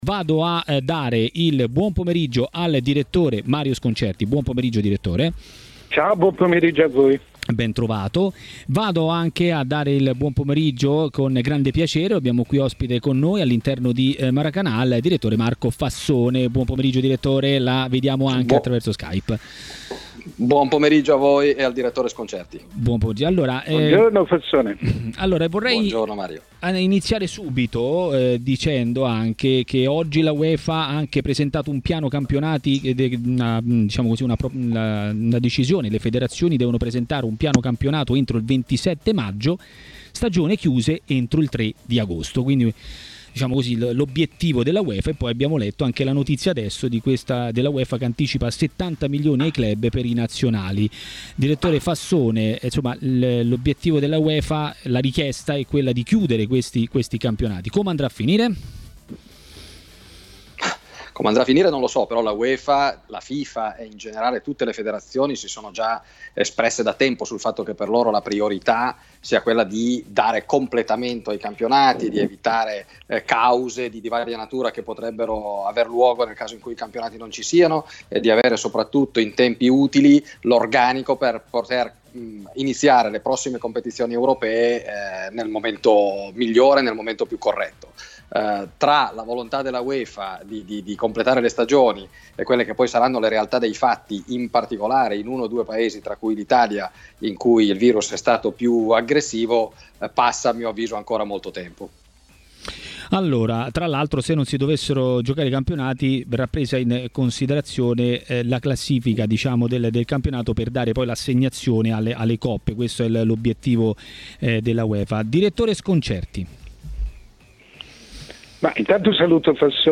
è intervenuto nel corso di Maracanà, trasmissione di TMW Radio.